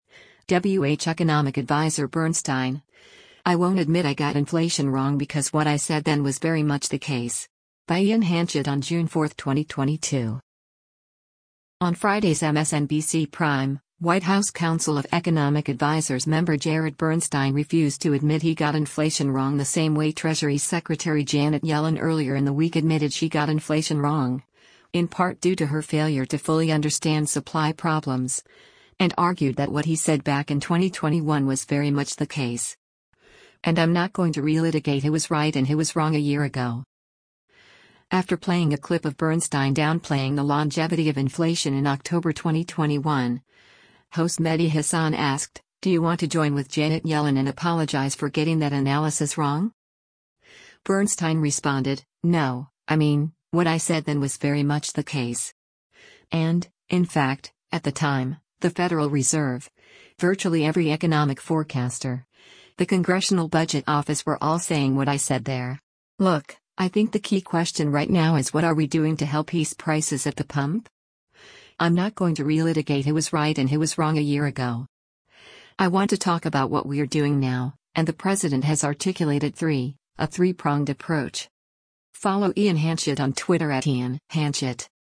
After playing a clip of Bernstein downplaying the longevity of inflation in October 2021, host Mehdi Hasan asked, “Do you want to join with Janet Yellen and apologize for getting that analysis wrong?”